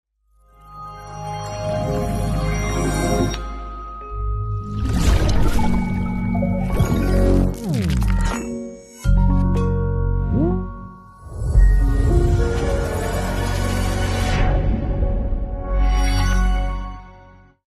💎 Sound design for 3D sound effects free download